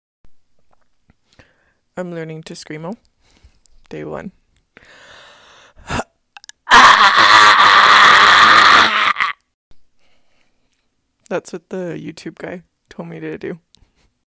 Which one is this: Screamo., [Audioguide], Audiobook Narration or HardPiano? Screamo.